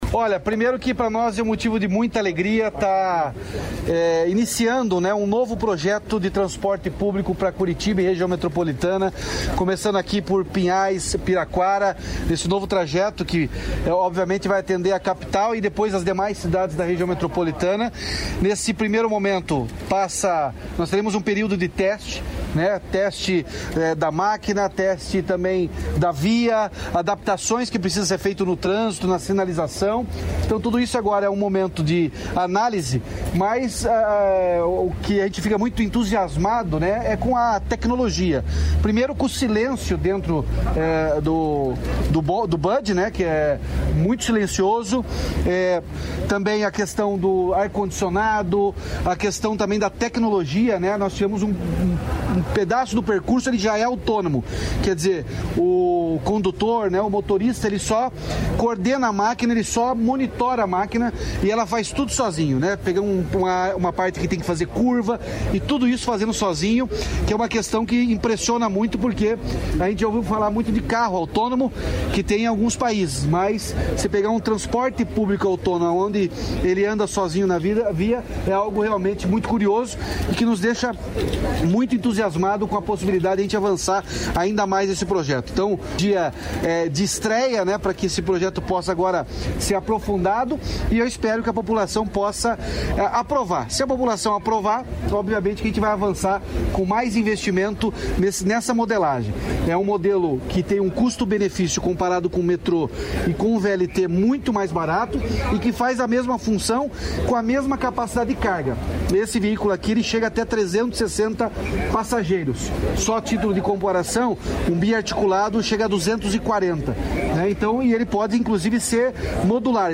Sonora do governador Ratinho Junior sobre o Bonde Urbano Digital